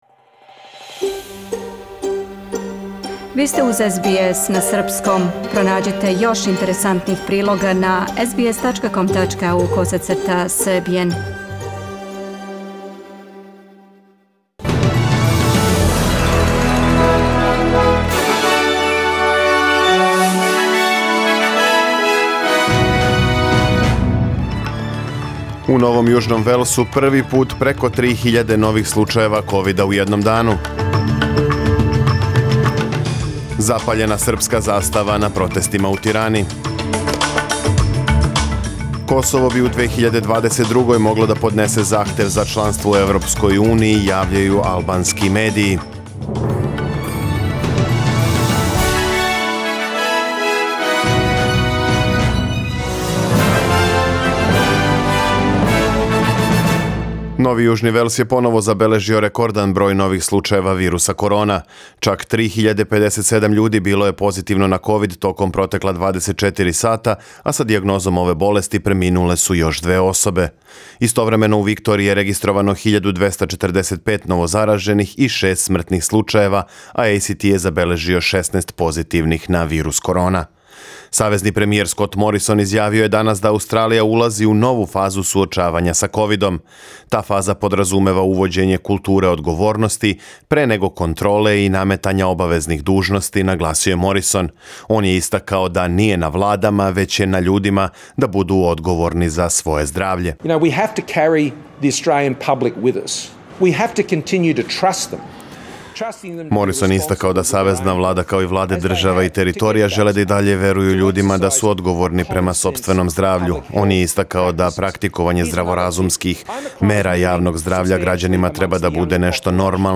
Serbian News Bulletin Source: SBS Serbian